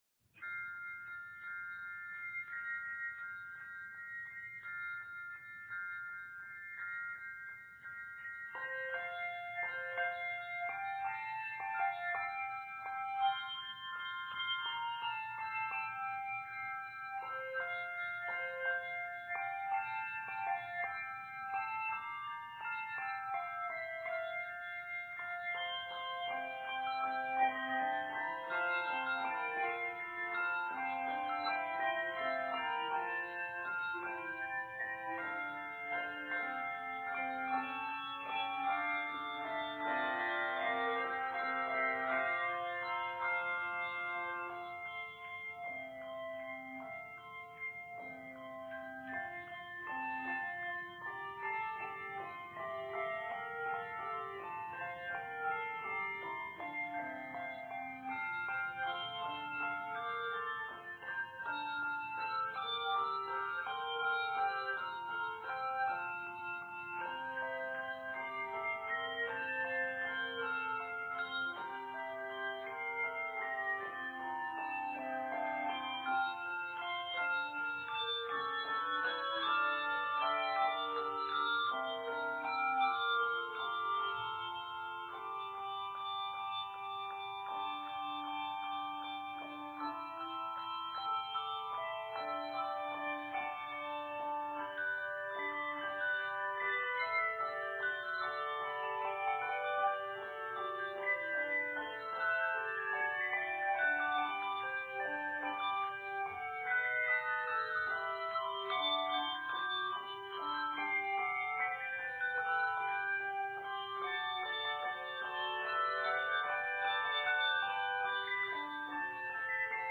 Designed for either a 3 octave handbell or chime choir
Set in F Major and G Major, measures total 60.